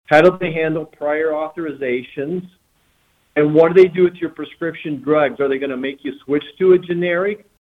Senator Marshall discusses Israel, Medicare enrollment during telephone town hall